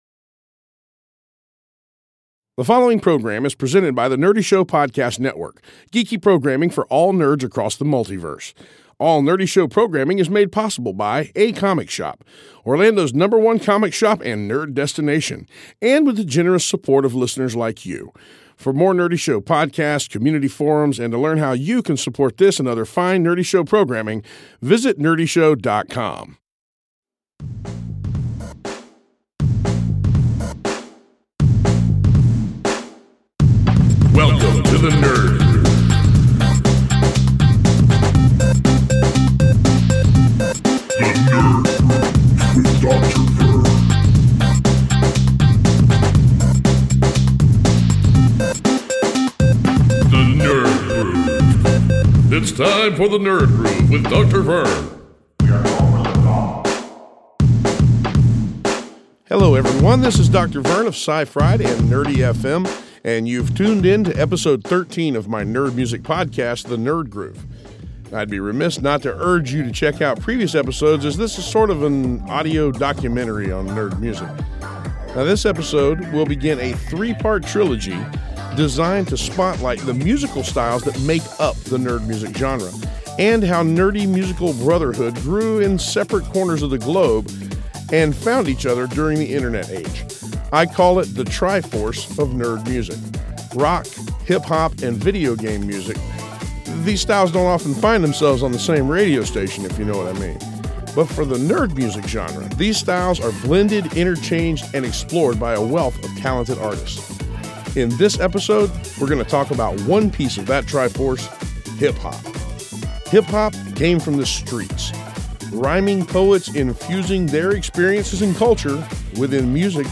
This episode it's all about the nerdcore family tree and the hip-hop influences it took root in. Plus we play some new nerdy tunes of all genes for good measure.